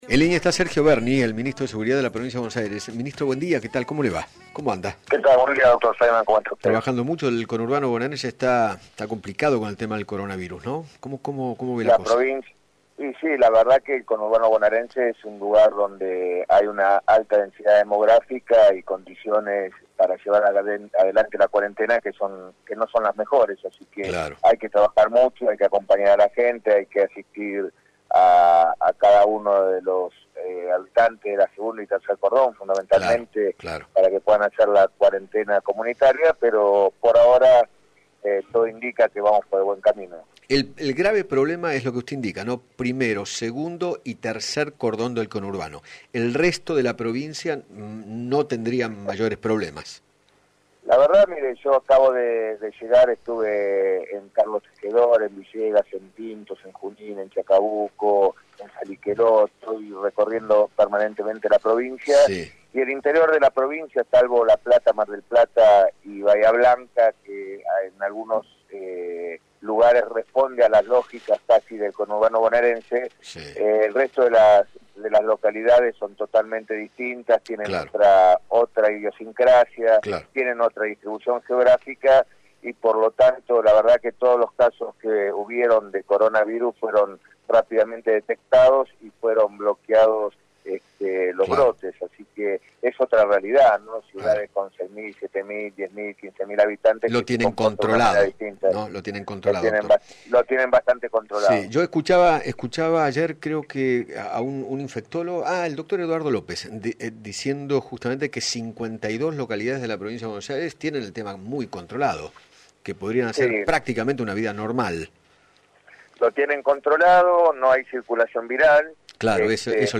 Sergio Berni, Ministro de Seguridad de la Provincia de Buenos Aires, dialogó con Eduardo